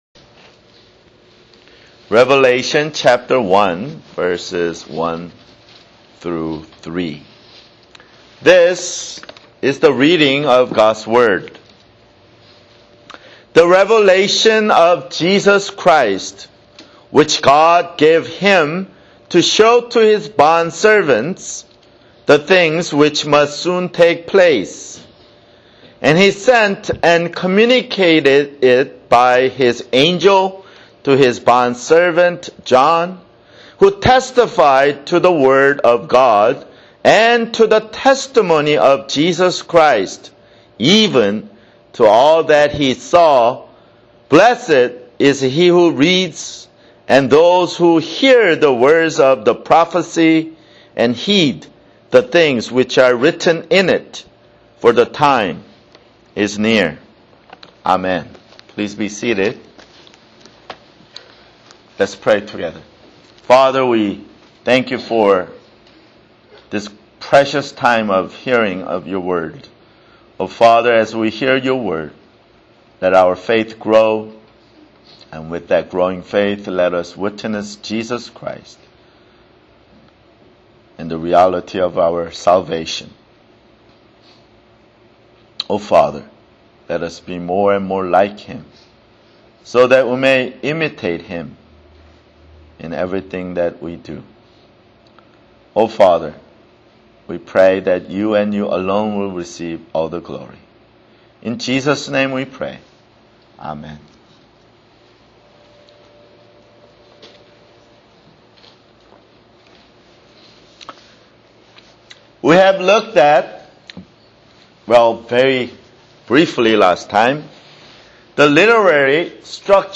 [Sermon] Revelation (3)